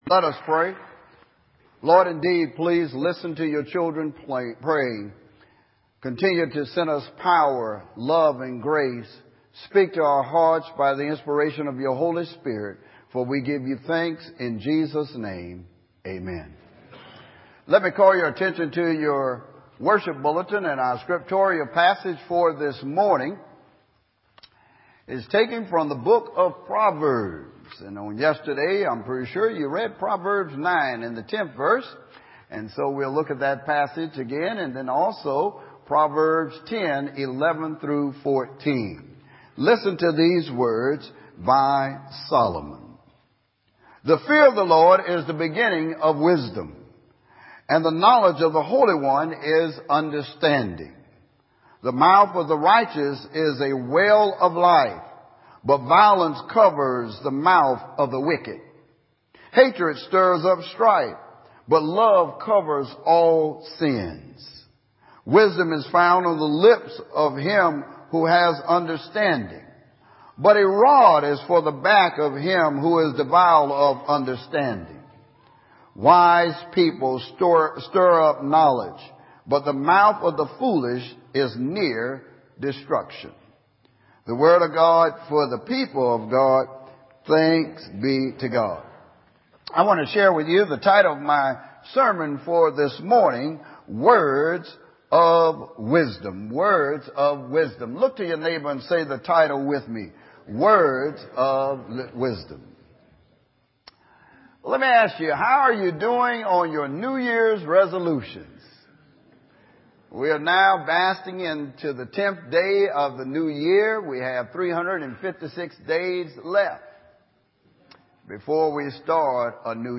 Palm Coast United Methodist Church Audio Sermons